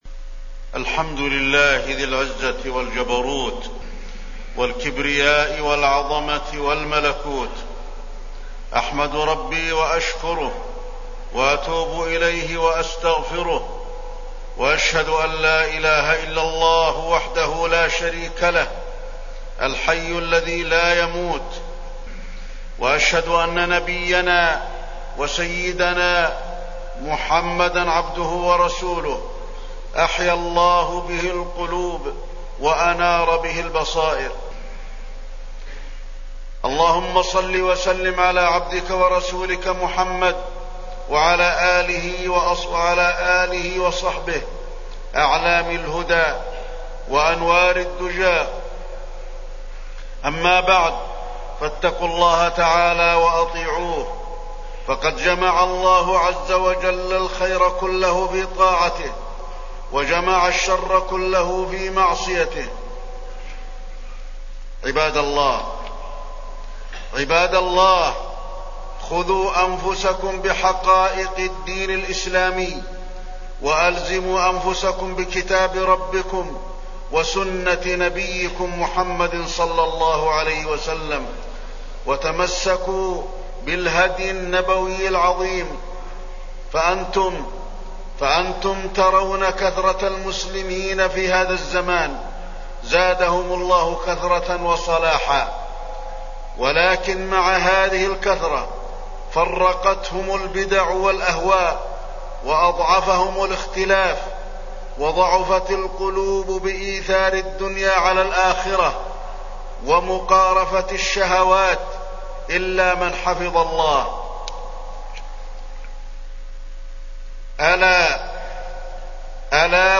تاريخ النشر ٧ ذو الحجة ١٤٢٩ هـ المكان: المسجد النبوي الشيخ: فضيلة الشيخ د. علي بن عبدالرحمن الحذيفي فضيلة الشيخ د. علي بن عبدالرحمن الحذيفي ذم البدع